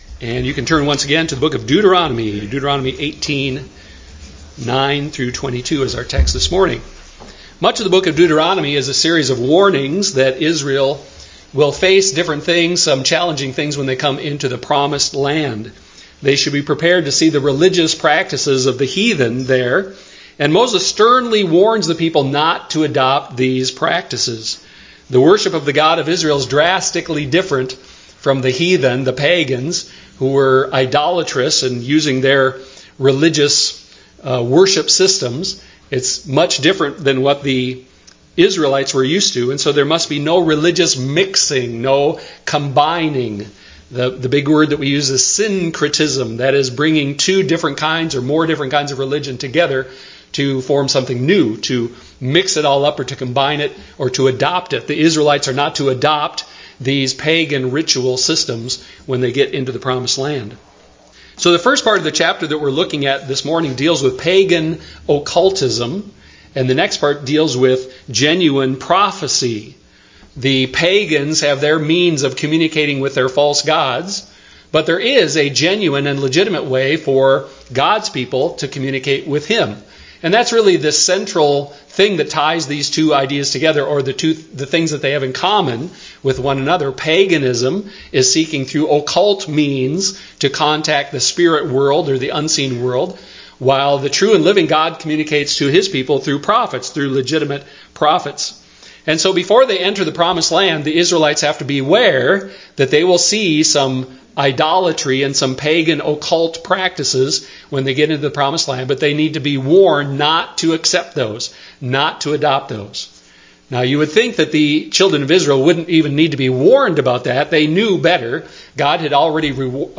Service Type: Sunday morning worship service